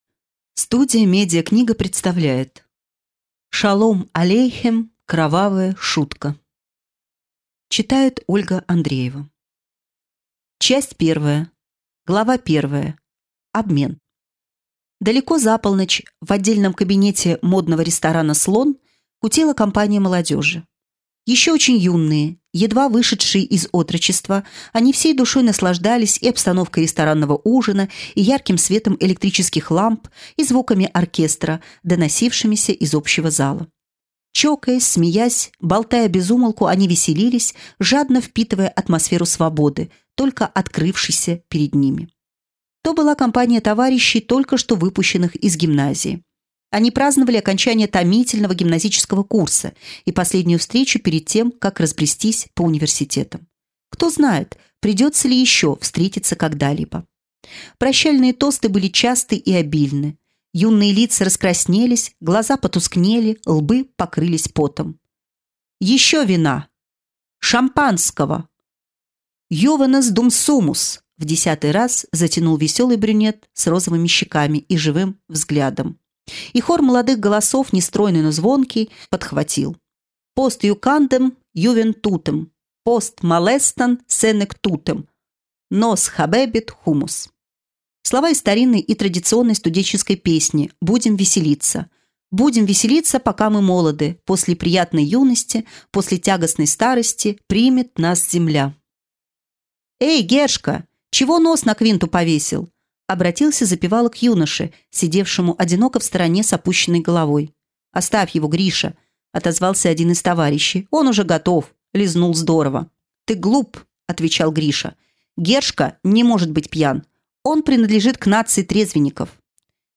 Аудиокнига Кровавая шутка | Библиотека аудиокниг
Прослушать и бесплатно скачать фрагмент аудиокниги